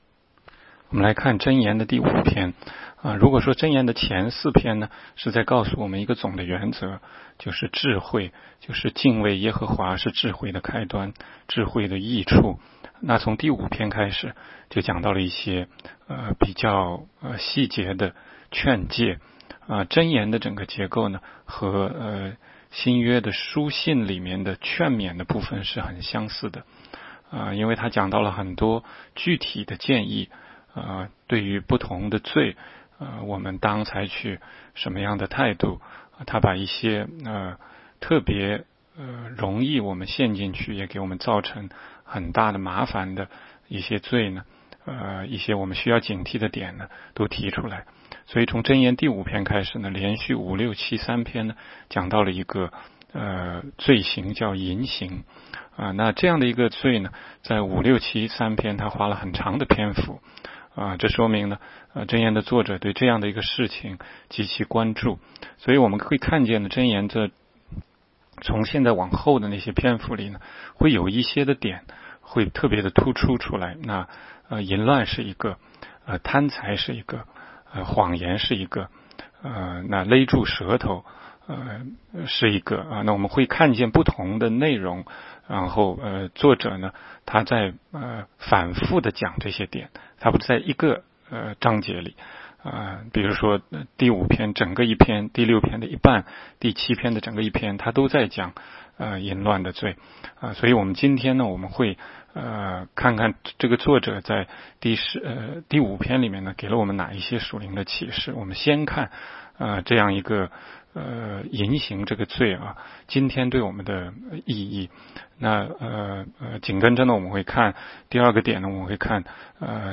16街讲道录音 - 每日读经 -《 箴言》5章